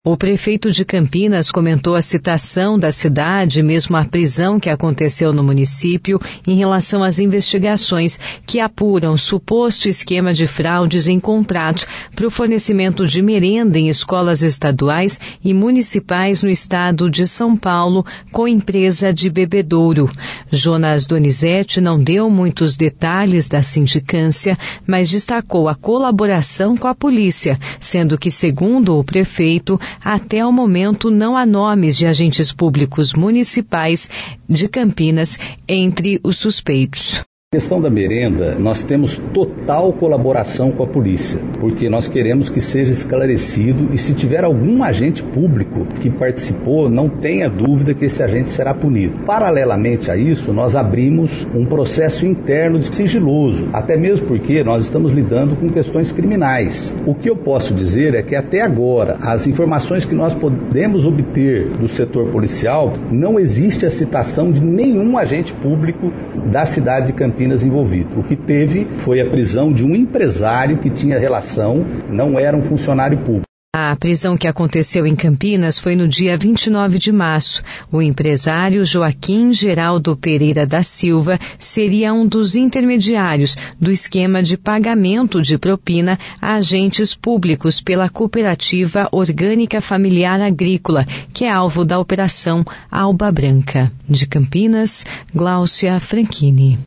Prefeito de Campinas comenta investigações sobre suposto esquema das merendas